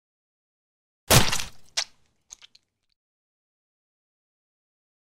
دانلود آهنگ پاشیدن خون 1 از افکت صوتی انسان و موجودات زنده
دانلود صدای پاشیدن خون 1 از ساعد نیوز با لینک مستقیم و کیفیت بالا
جلوه های صوتی